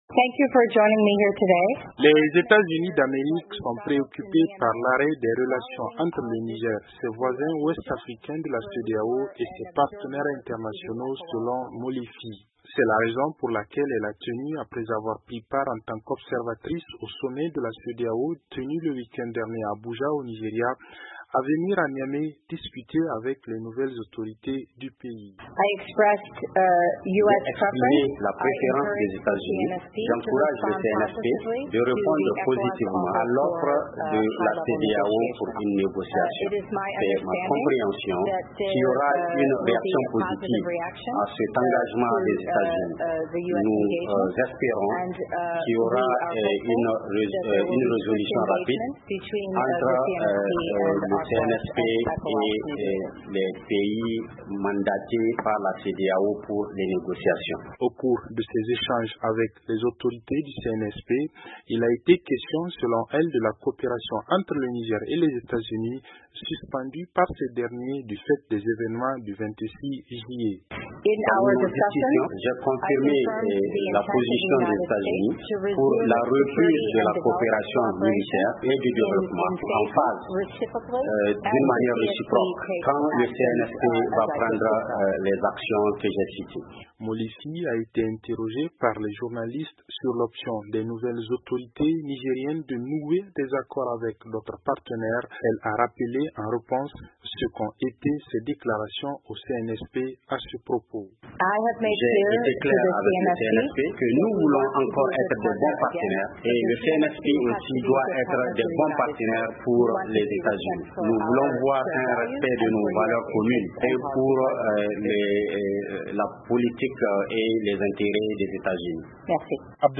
Au cours de sa visite, elle a rencontré le Premier ministre de transition ainsi que des membres de la junte, les exhortant, conformément aux exigences de la CEDEAO, à présenter un calendrier pour un retour rapide à la démocratie. Reportage de notre correspondant